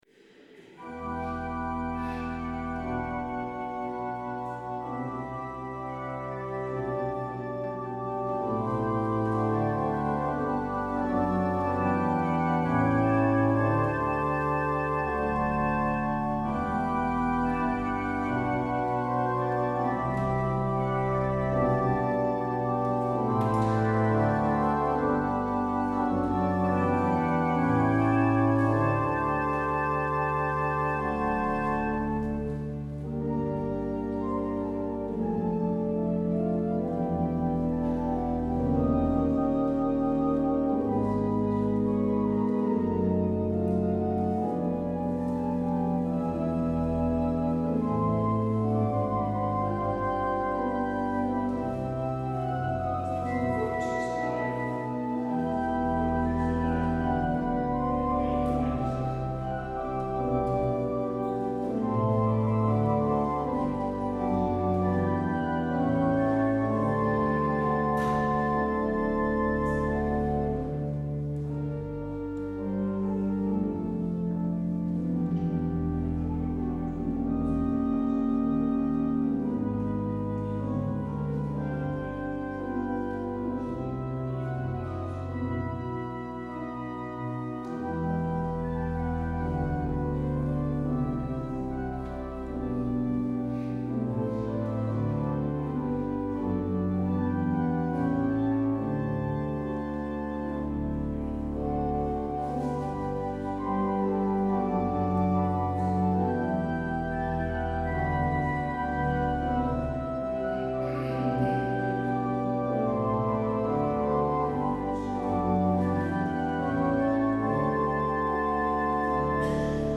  Luister deze kerkdienst hier terug
Het openingslied is NLB 513: 1, 2, 3 en 4.